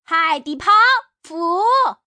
Index of /poker_paodekuai/update/1526/res/sfx/changsha_woman/